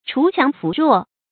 锄强扶弱 chú qiáng fú ruò 成语解释 铲除强暴，扶助弱者。